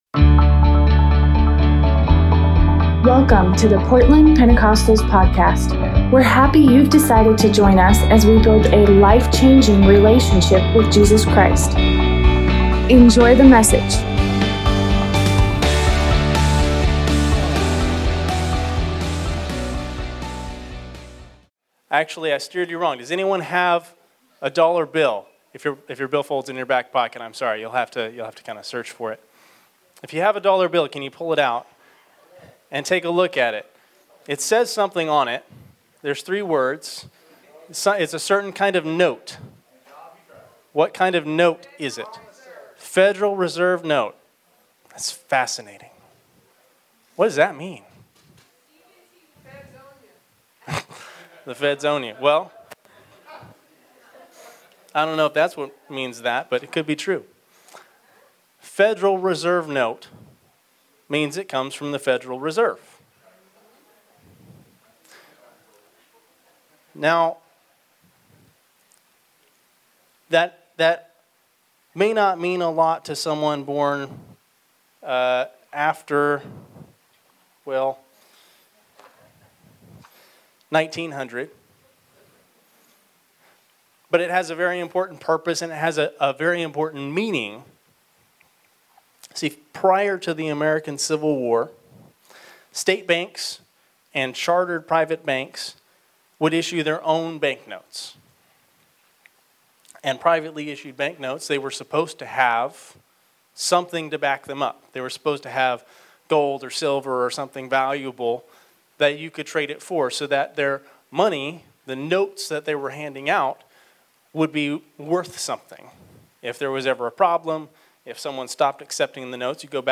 2022 Spent Preacher